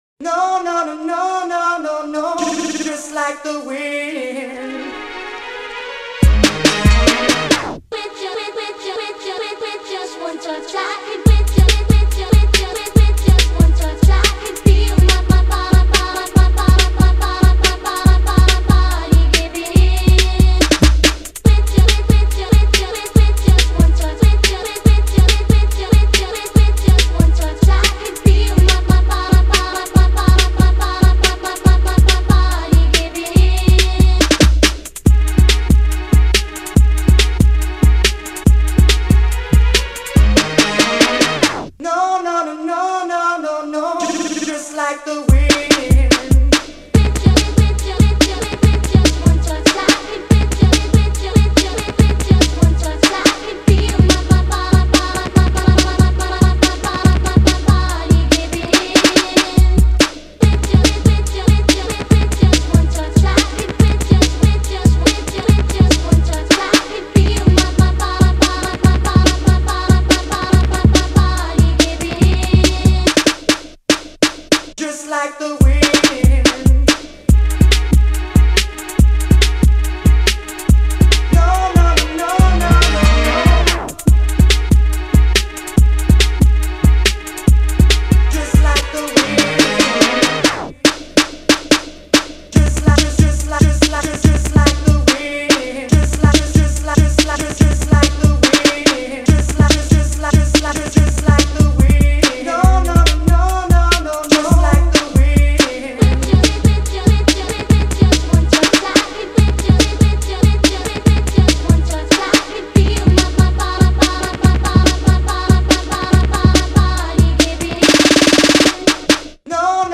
با ریتمی سریع شده